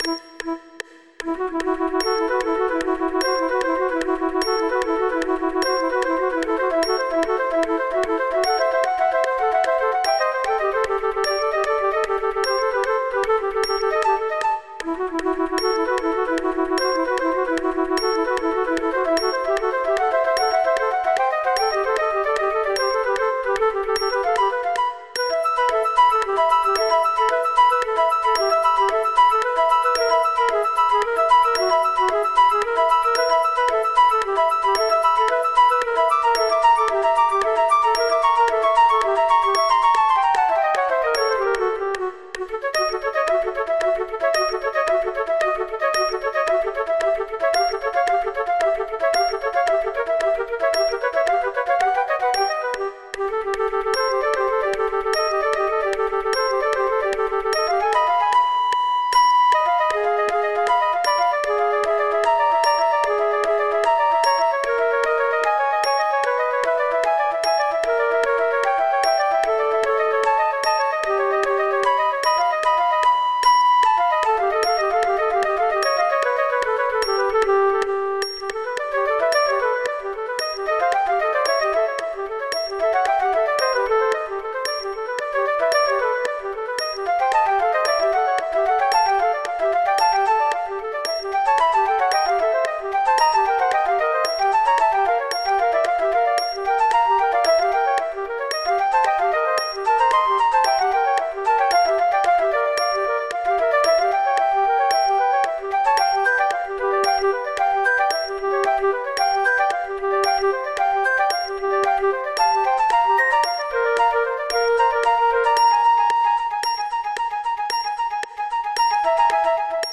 This trio for three flutes is full of aural illusions.
With metronome clicks (and apologies for a strange metronome artefact right at the beginning),
and with the third flute missing there are
full speed.